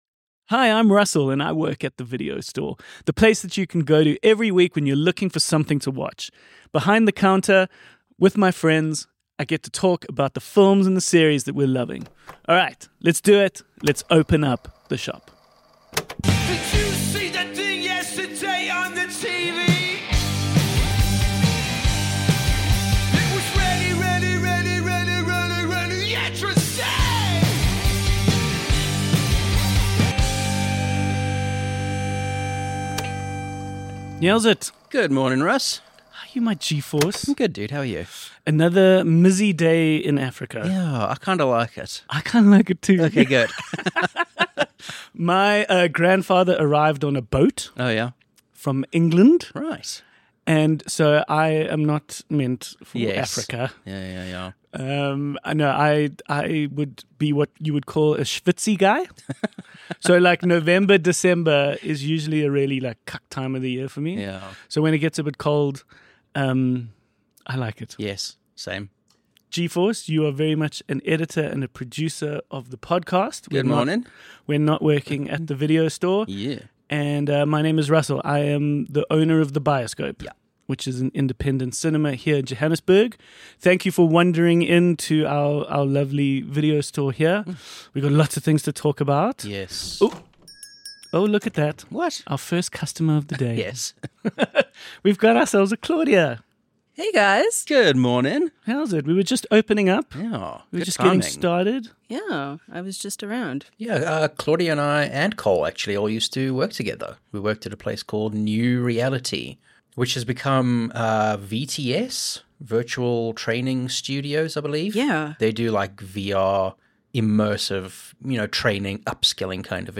A weekly chat amongst friends working a shift at your local video store.